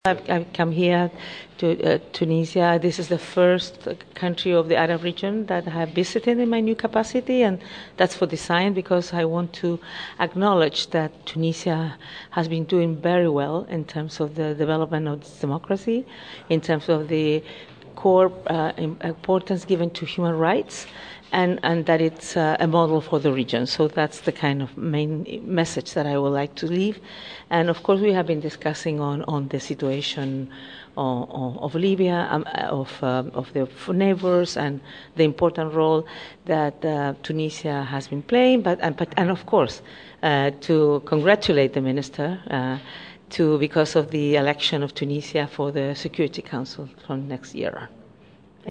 تصريح المفوضة السامية لحقوق الانسان عقب لقائها بوزير الخارجية
قالت المفوضة السامية لحقوق الانسان بالامم المتحدة ميشال باشليه في تصريح لمراسلة "الجوهرة أف أم" عقب لقاء جمعها مع وزيرالشؤون الخارجية خميس الجهيناوي، انه تم التطرّق خلال الى مسائل ذات اهتمام مشترك لاسيما الملف الليبي ومتابعة التوصيات في مجال حقوق الانسان بتونس .